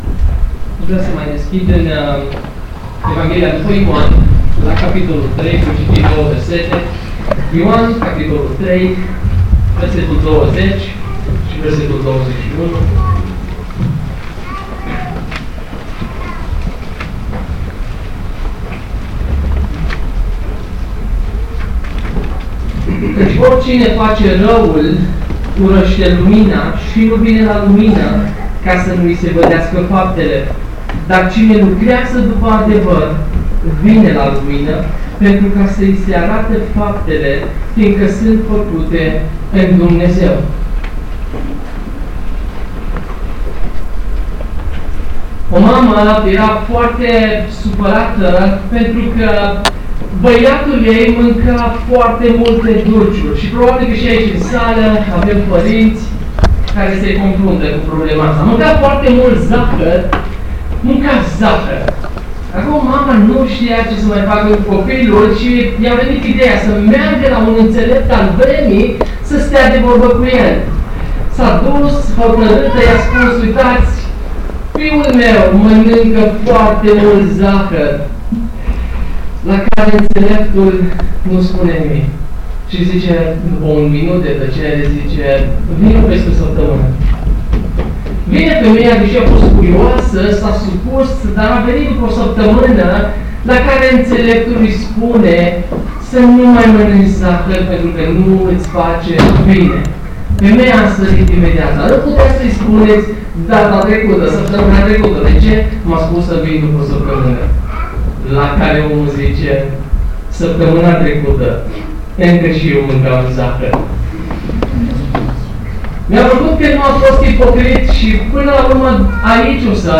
Evanghelizare, dimineața Predică